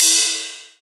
• Crash Single Hit F# Key 04.wav
Royality free cymbal crash sound tuned to the F# note. Loudest frequency: 6255Hz
crash-single-hit-f-sharp-key-04-m3P.wav